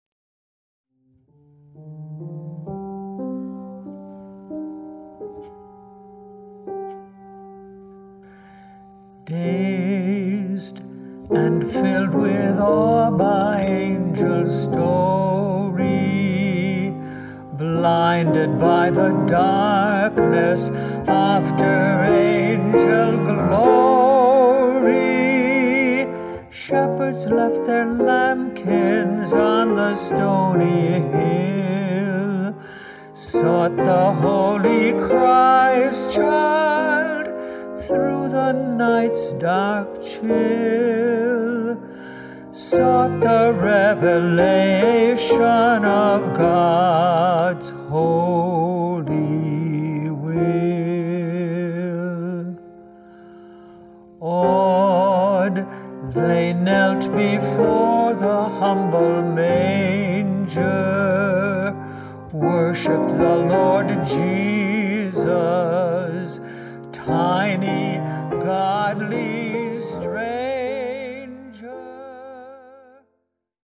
Gifted at both piano and song
a true Christmas inspirational CD